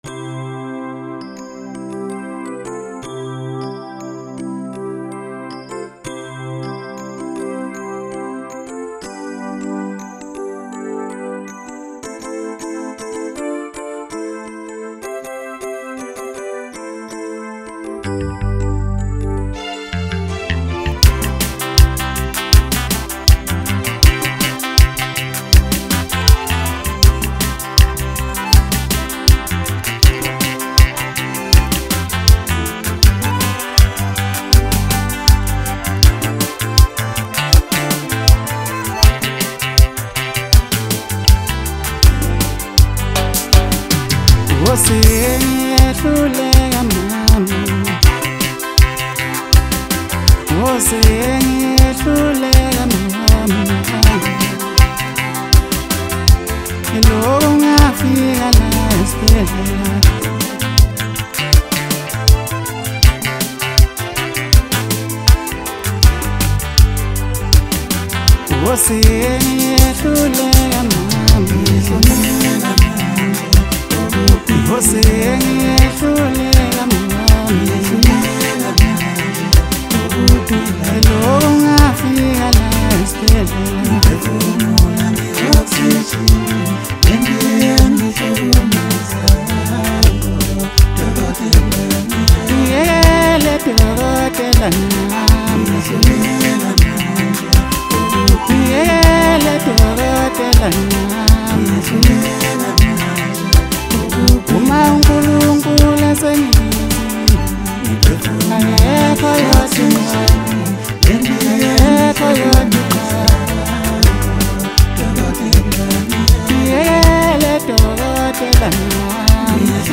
Maskandi Artist